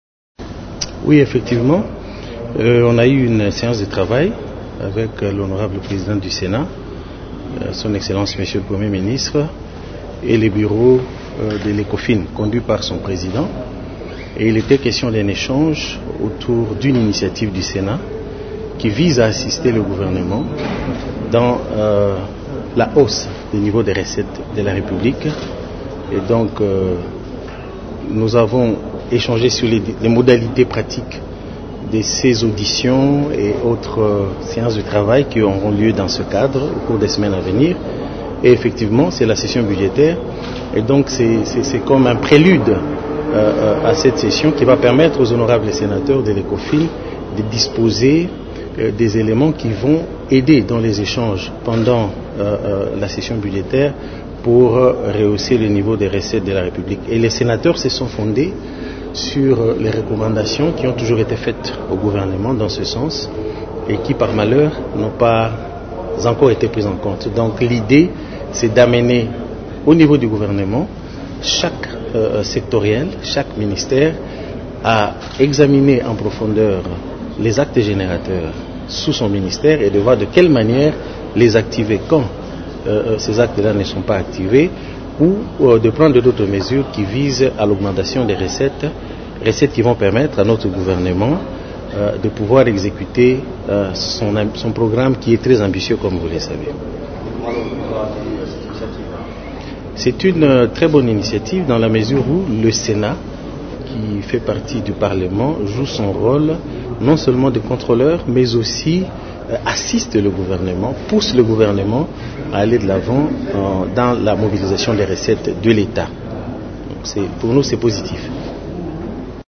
Les membres de la commission économique et financière du Sénat ont pris part à cette rencontre, à laquelle était aussi associé le ministre du Budget, Aimé Boji Sangara. Ce dernier a salué cette initiative dans cet extrait :